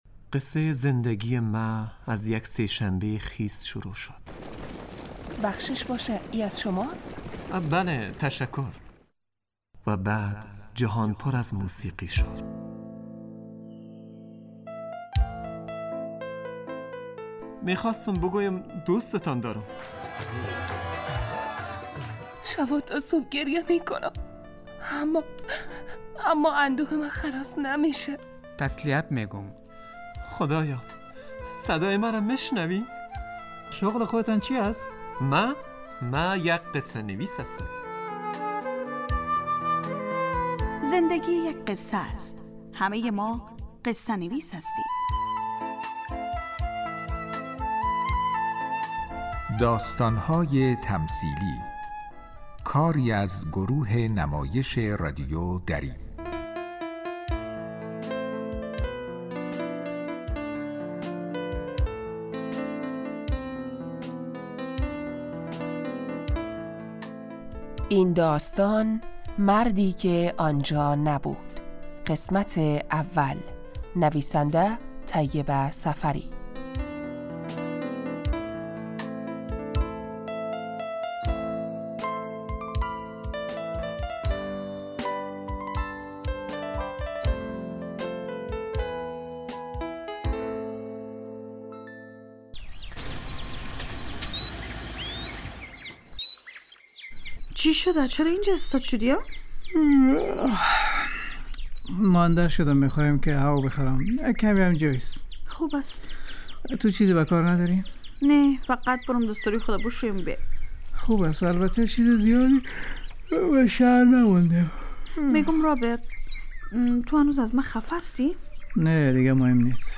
داستان های تمثیلی یک برنامه 15 دقیقه ای در قالب نمایش رادیویی می باشد که همه روزه به جز جمعه ها از رادیو دری پخش می شود موضوع اکثر این نمایش ها پرداختن به...